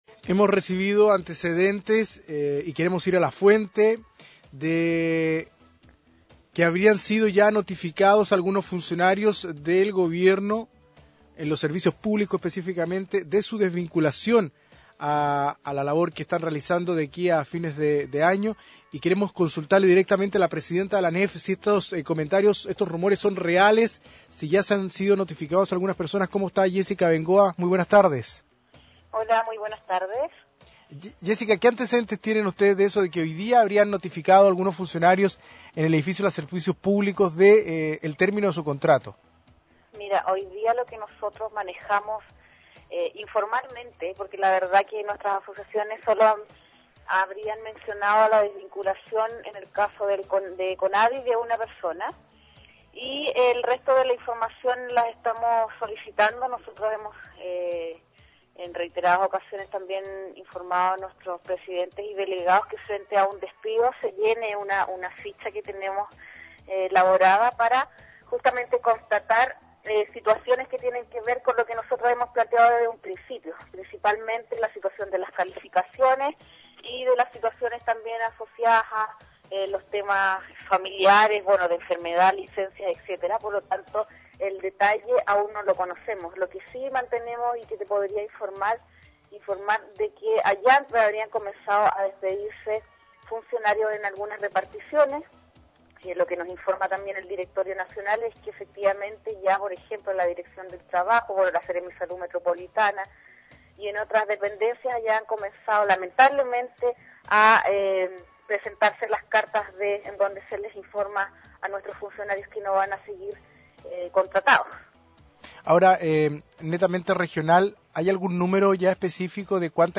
Entrevistas de Pingüino Radio